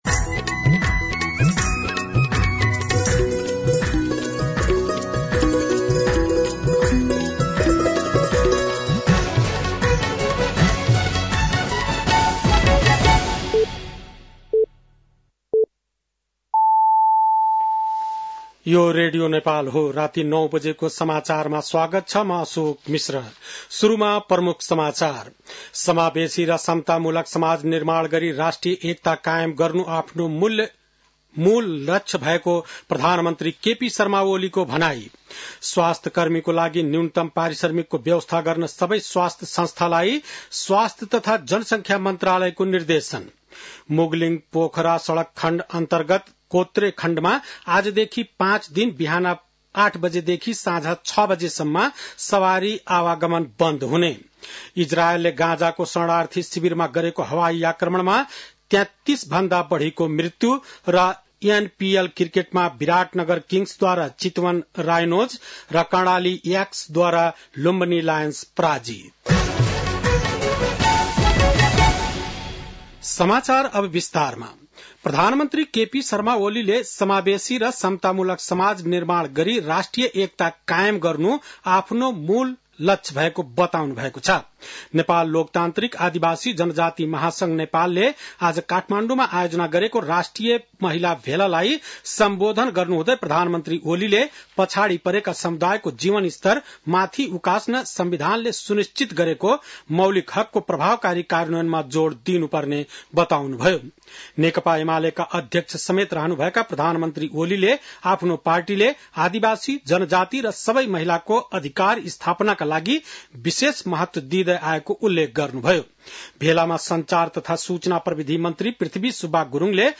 बेलुकी ९ बजेको नेपाली समाचार : २९ मंसिर , २०८१